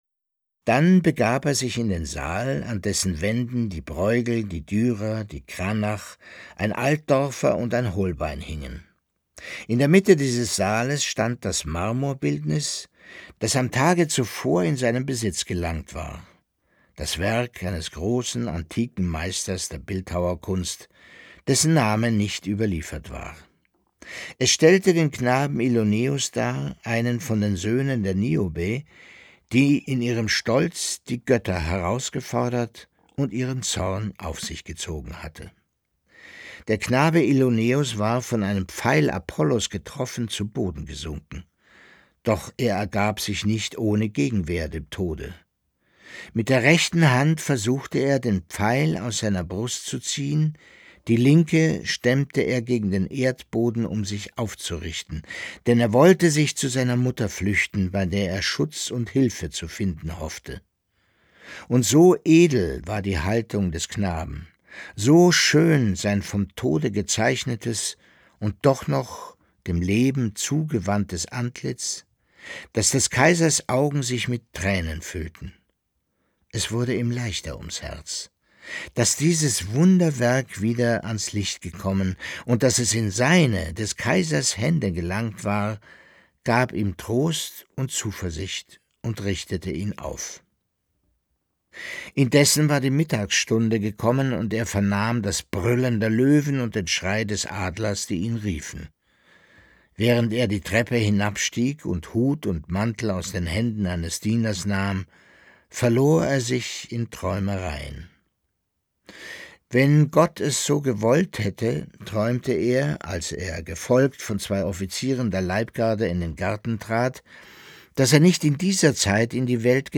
Leo Perutz: Nachts unter der steinernen Brücke (24/25) ~ Lesungen Podcast